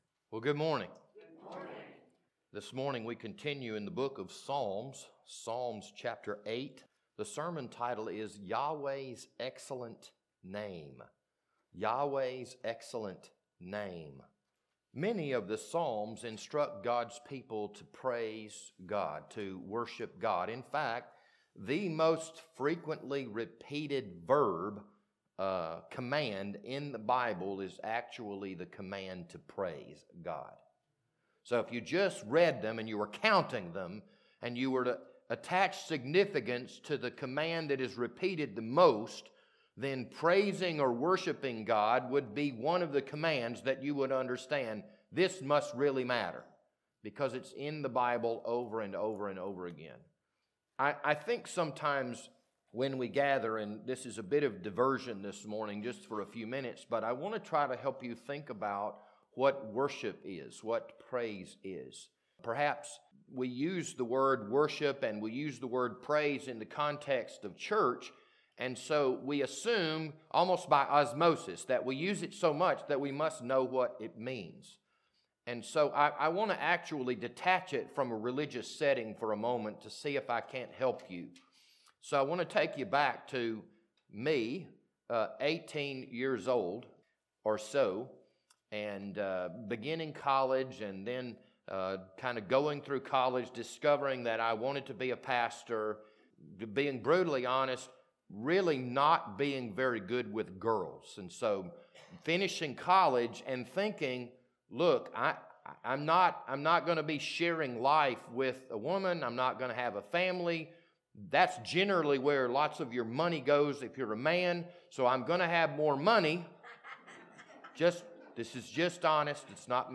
This Sunday morning sermon was recorded on January 30th, 2022.